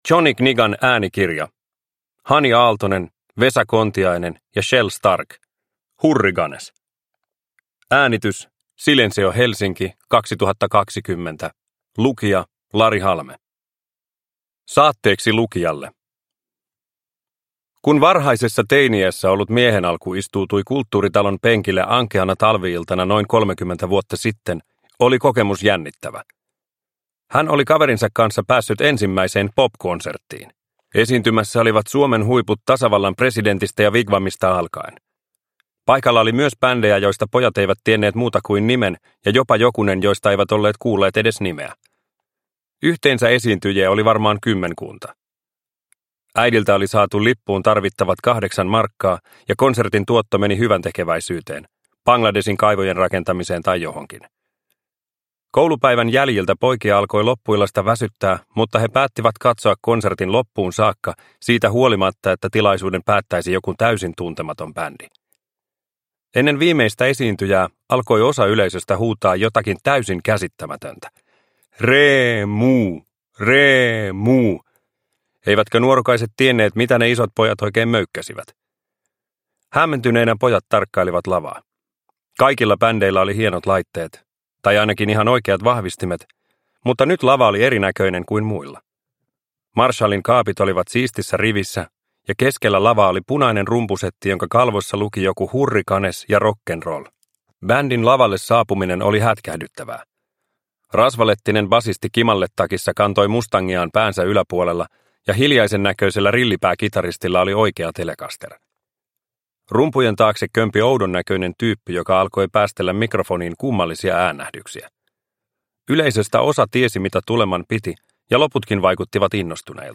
Hurriganes – Ljudbok – Laddas ner